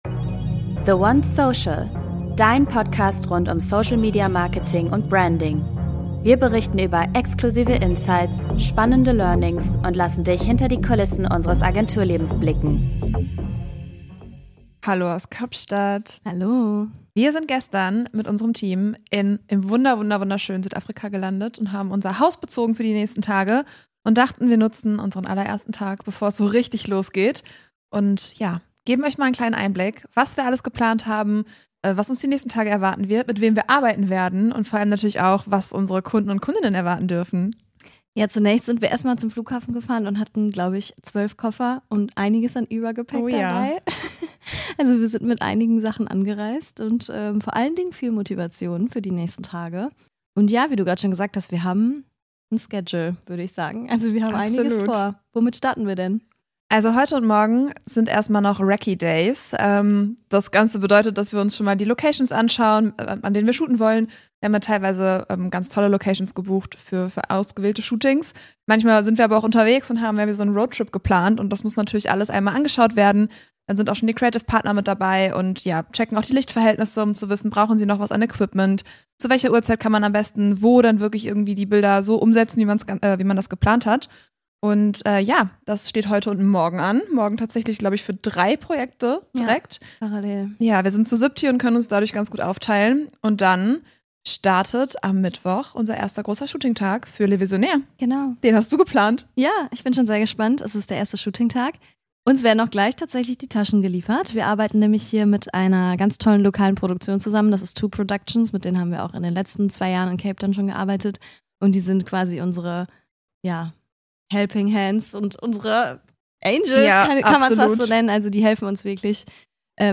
#23 Sprachmemo aus Cape Town ~ THE WANTS PODCAST Podcast
Hello again aus dem wunderschönen Kapstadt!